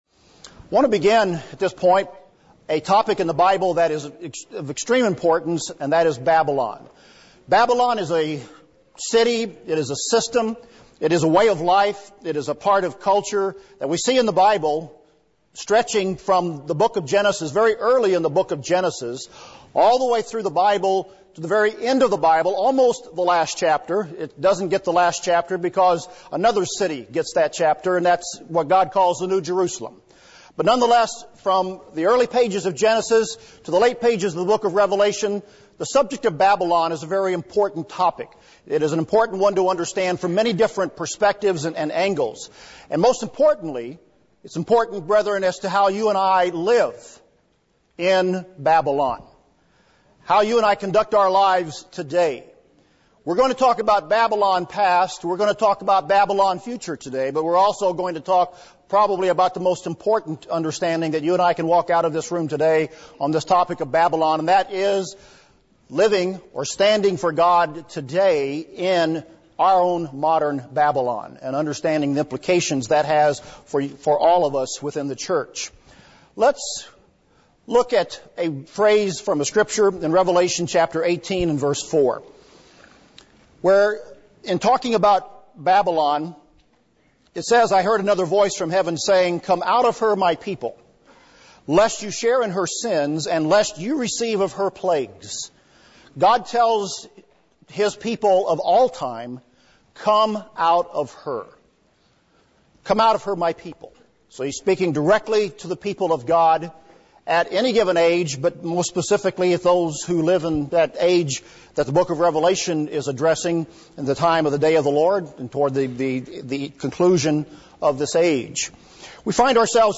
World News and Prophecy Seminar